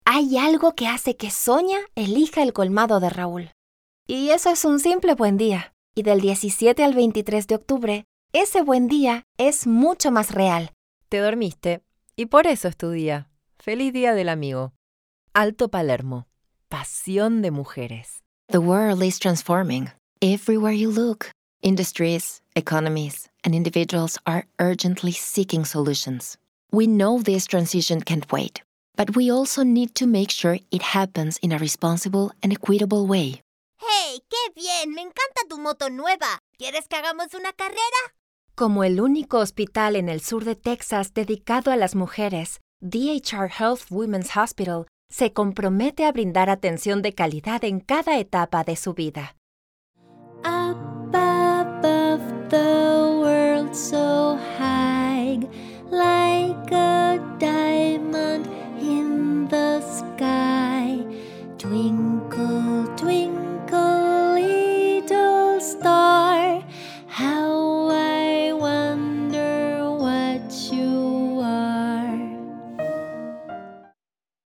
Doblaje de Voz para Video Juegos
Dynamic, commercial, warm, friendly, and inspiring voice with different shades according to the client's needs.